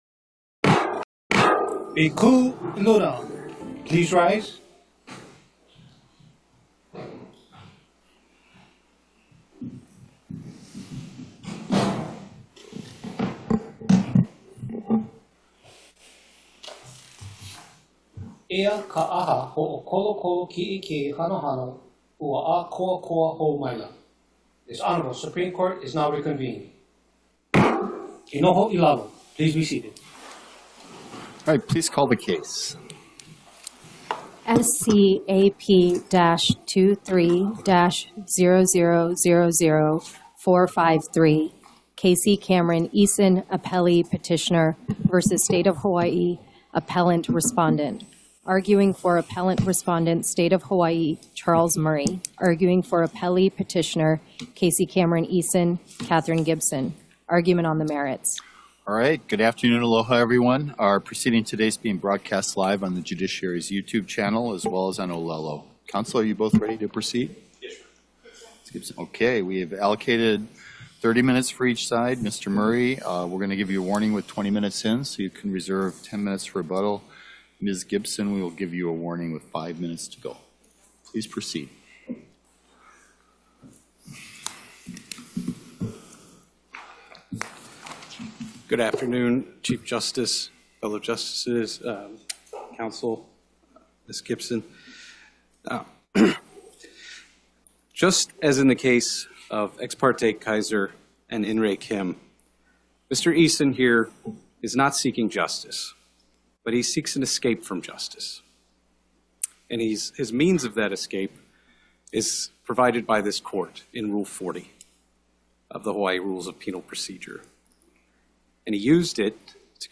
The above-captioned case has been set for oral argument on the merits at: Supreme Court Courtroom Ali ‘ iōlani Hale, 2 nd Floor 417 South King Street Honolulu, HI 96813